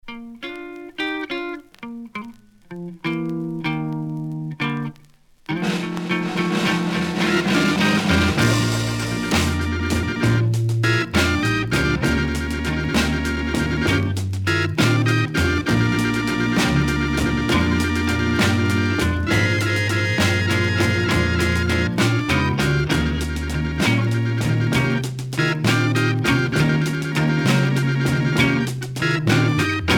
Beat instrumental Deuxième EP retour à l'accueil